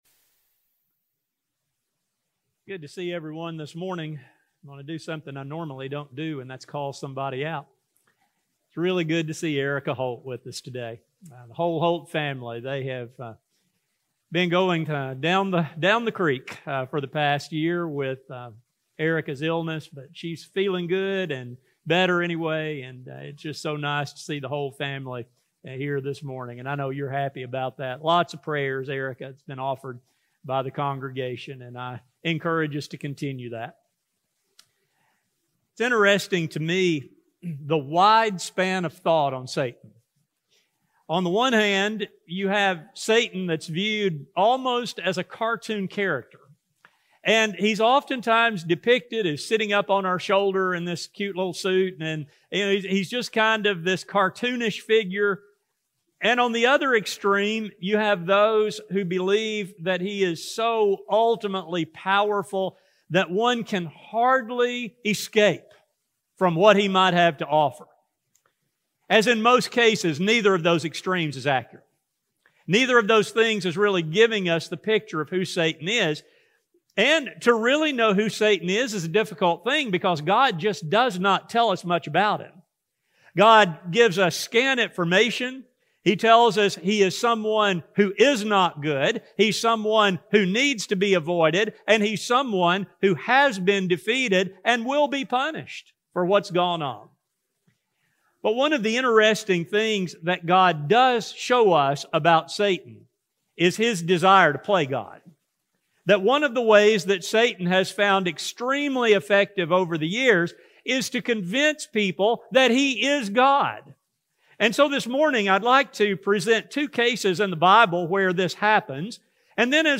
This study focuses on Satan’s techniques and how Christians must be on guard not to fall for his lies. A sermon recording